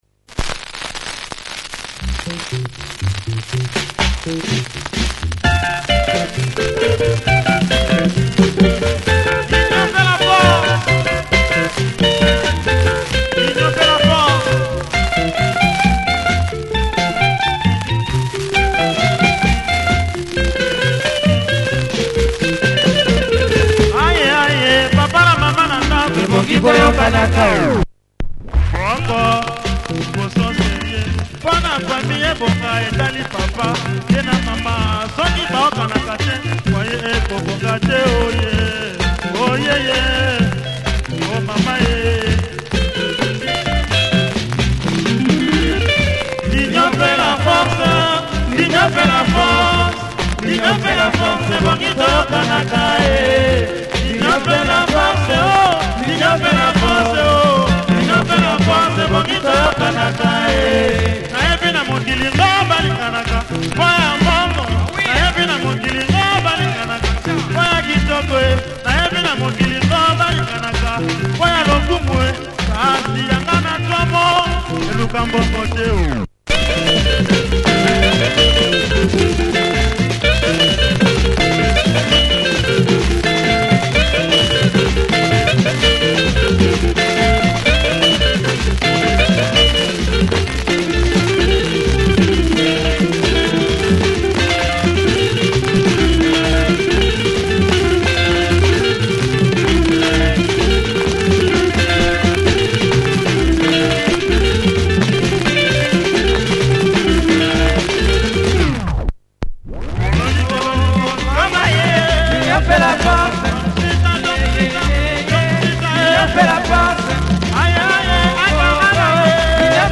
Nice break on the drums and bass.